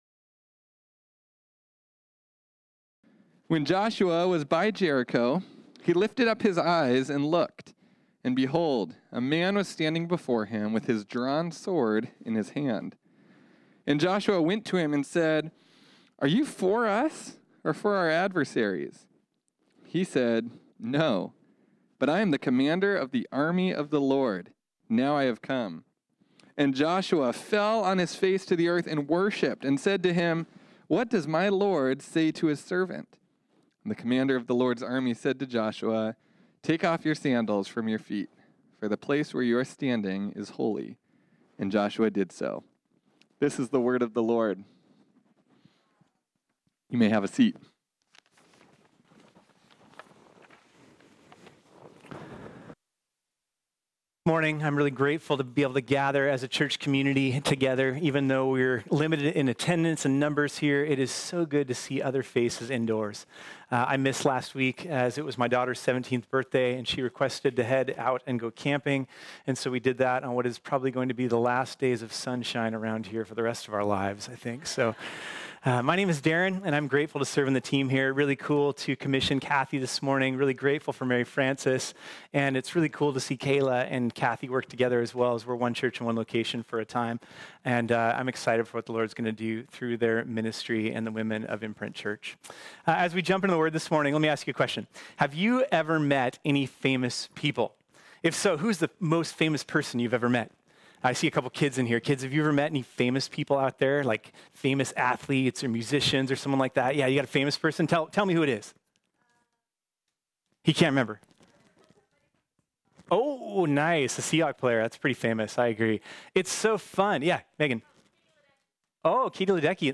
This sermon was originally preached on Sunday, October 11, 2020.